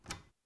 Open Closet.wav